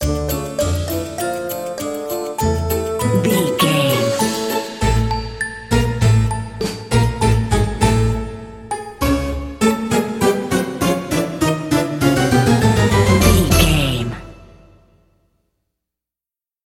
Aeolian/Minor
D
orchestra
harpsichord
silly
circus
goofy
comical
cheerful
perky
Light hearted
quirky